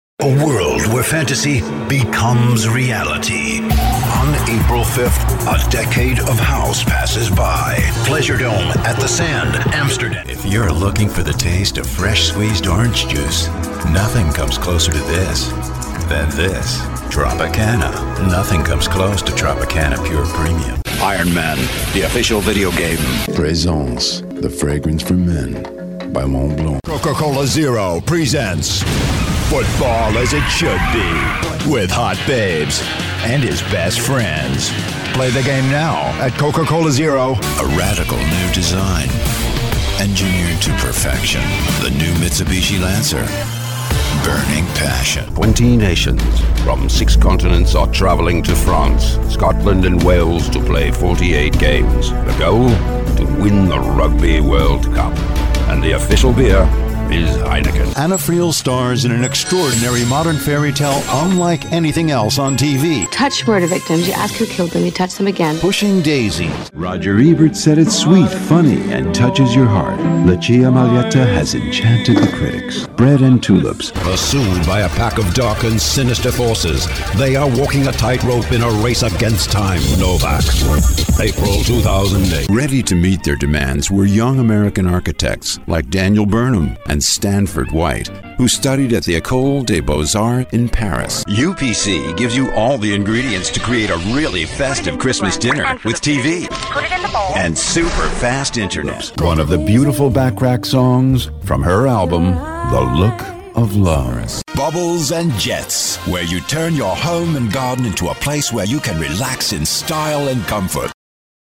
Bass / Baritone with textured and powerful voice. Very flexible from Dark and dramatic to warm and conversational.
Profi-Sprecher englisch (usa). Trailerstimme, Werbesprecher.
Sprechprobe: Werbung (Muttersprache):